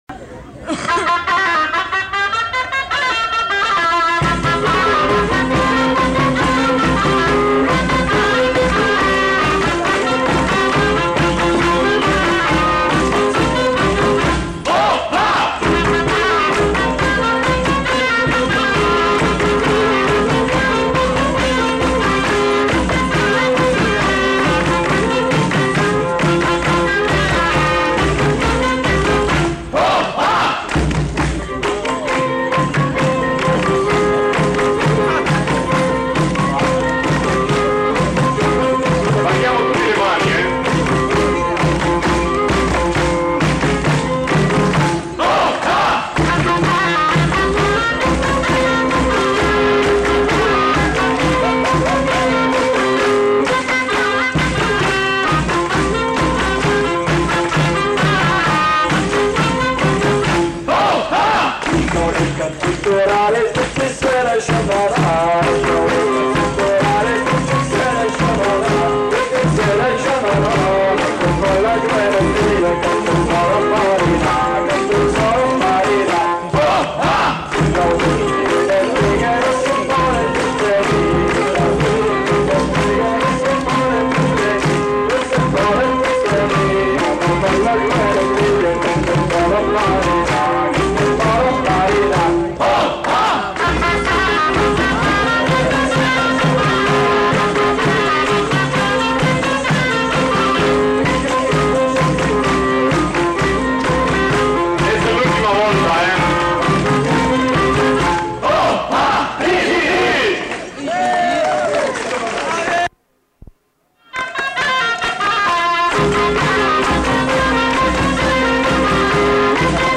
Aire culturelle : Pays d'Oc
Lieu : Pinerolo
Genre : morceau instrumental
Instrument de musique : graile ; violon ; percussions
Danse : bourrée d'Ariège